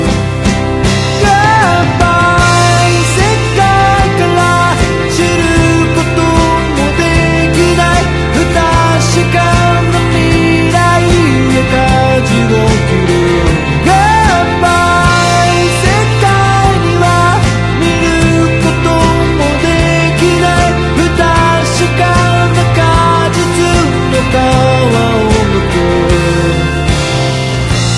Reduced quality: Yes (65 kbps)